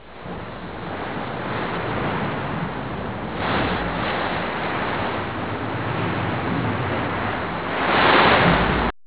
gusts.aiff